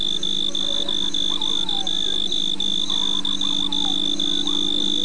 cricket2.mp3